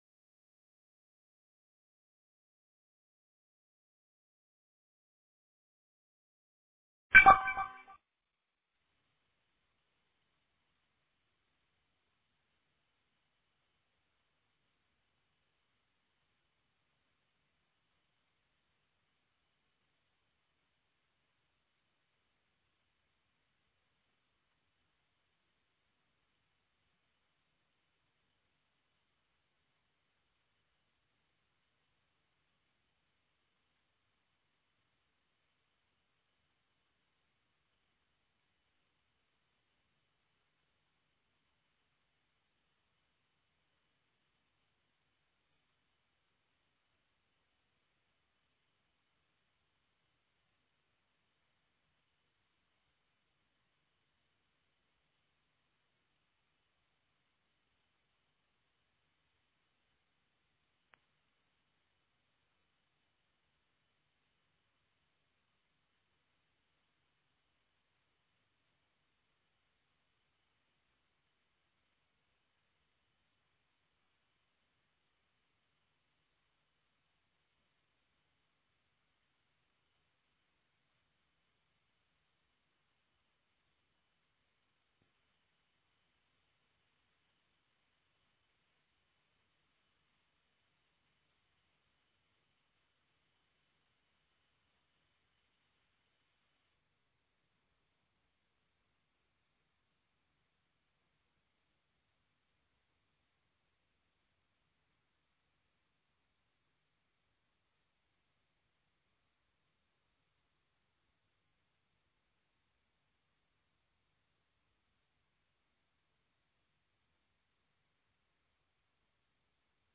Seduta del consiglio comunale - 17.06.2025